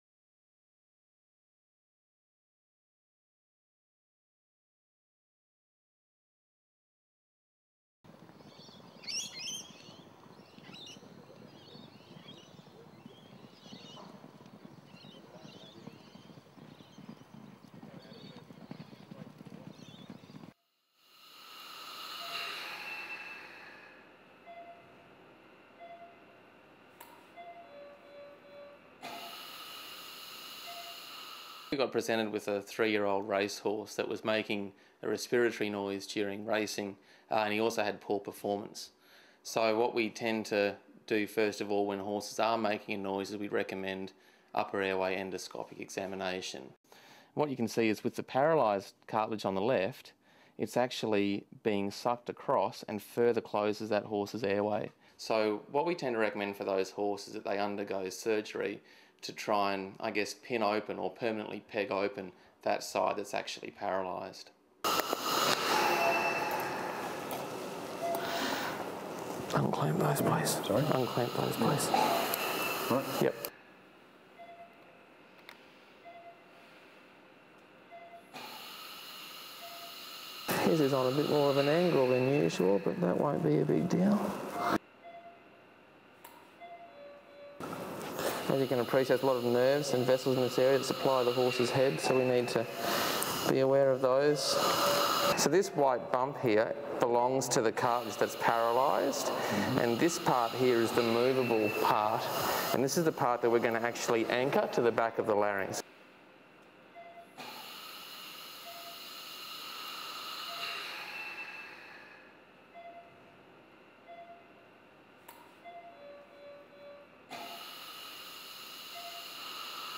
Horse Roaring this is Roaring Horse Tie Back Surgery.mp4